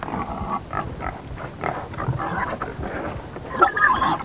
schweine.mp3